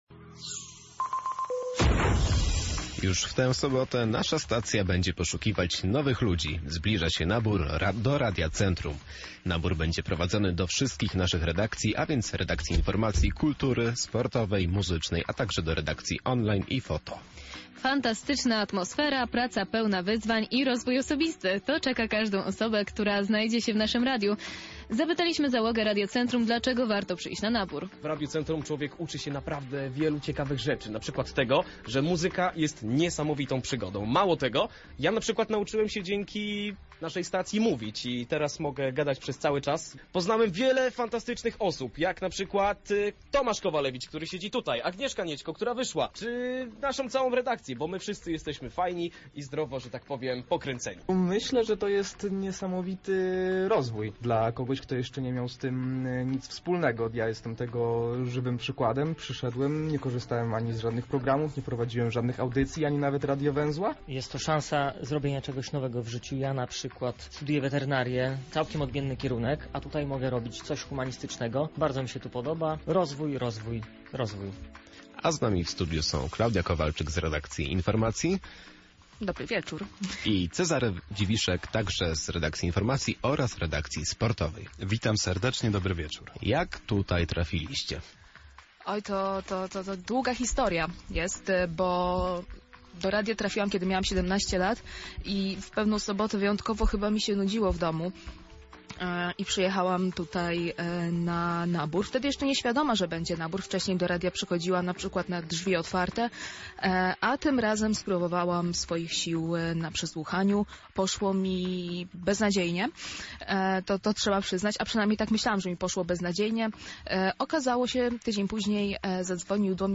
O naszej stacji wypowiedzieli się nasi dziennikarze redakcji informacji i sportowej: